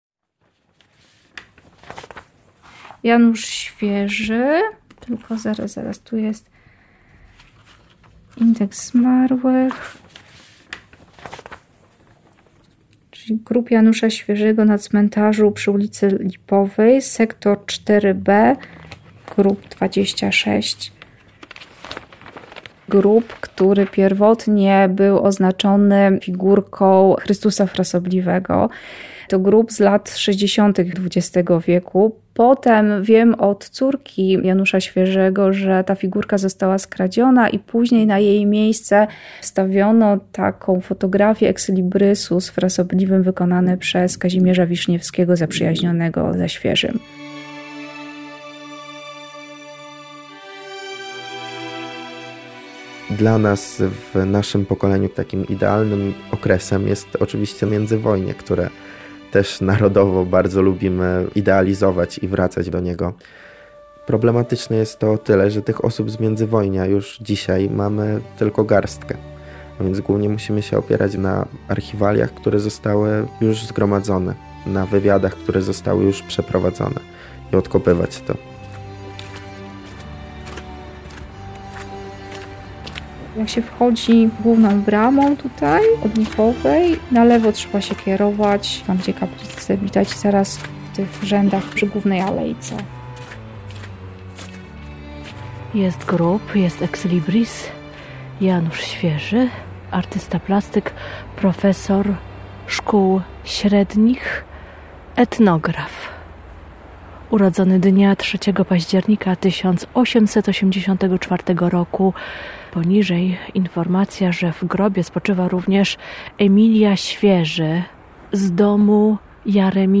Reportaż o Januszu Świeżym – polskim grafiku, rysowniku, pedagogu, zafascynowanym kulturą ludową i etnografią. Zbiory sztuki ludowej w Muzeum Narodowym w Lublinie zawdzięczają mu sporo eksponatów.